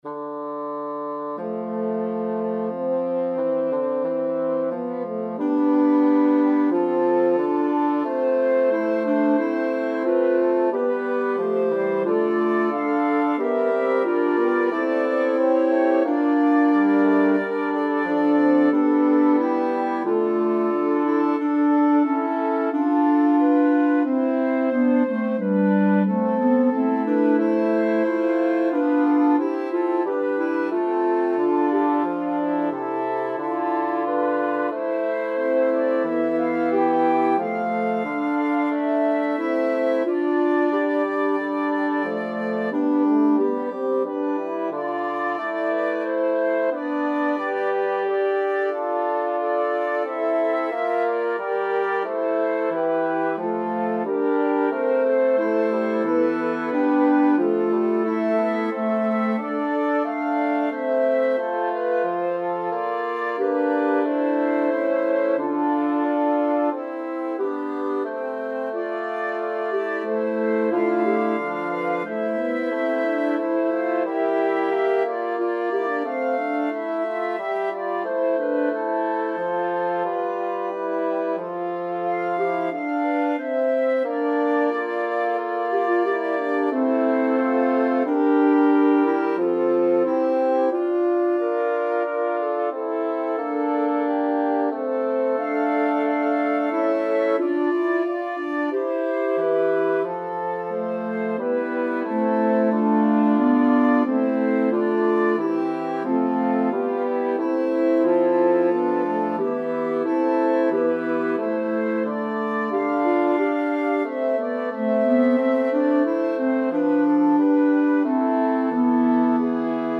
Manchicourt, Recordare Domine à 5 (alla quarta bassa).mp3
Number of voices: 5vv Voicings: SAATB or ATTTB Genre: Sacred, Motet
Language: Latin Instruments: A cappella